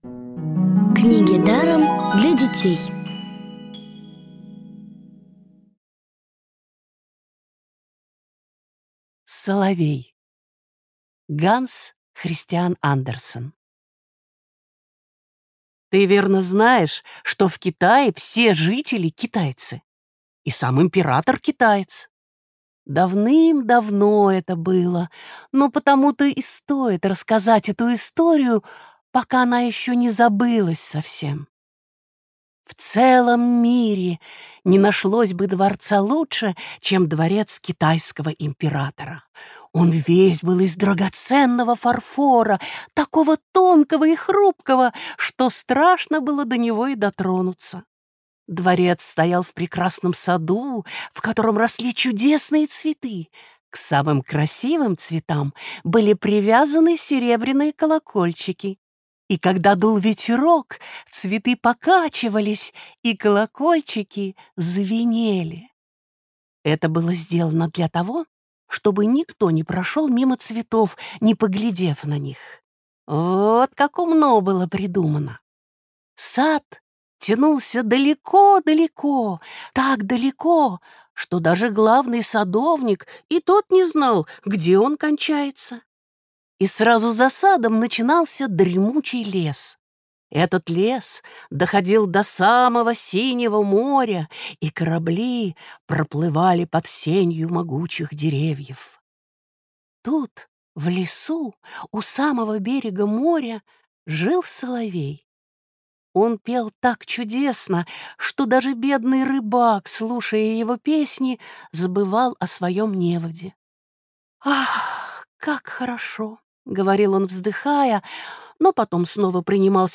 Аудиокниги онлайн – слушайте «Соловья» в профессиональной озвучке и с качественным звуком. Ханс Кристиан Андерсен - Соловей.